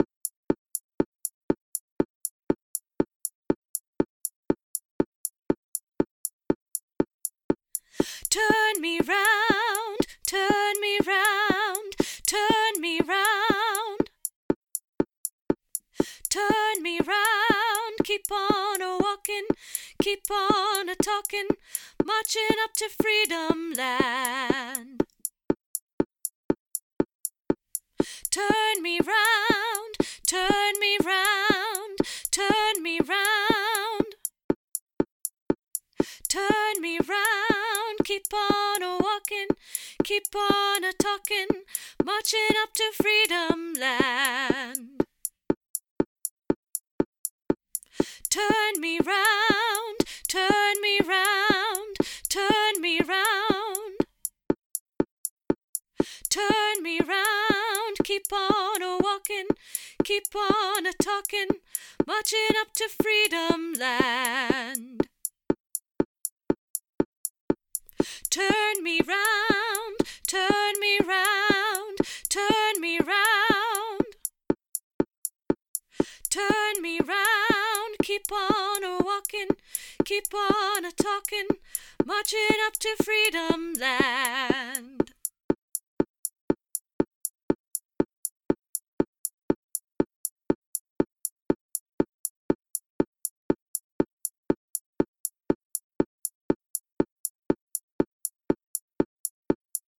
AGLN Alto